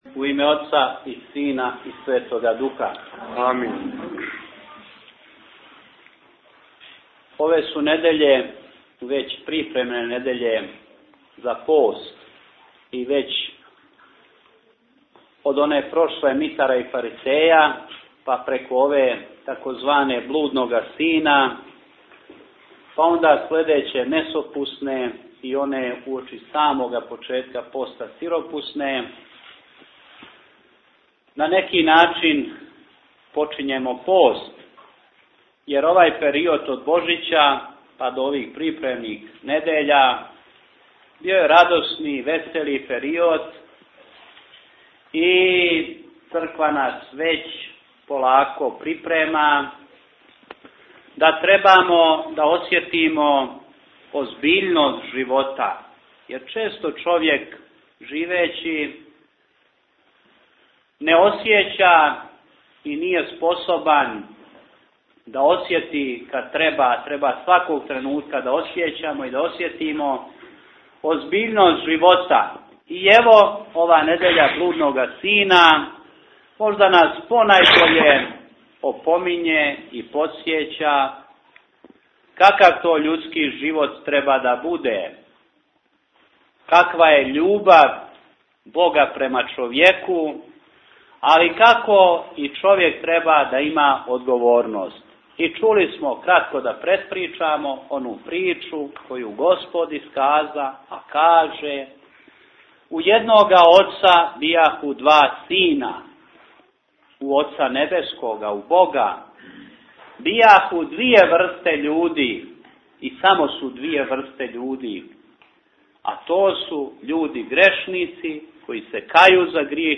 Tagged: Бесједе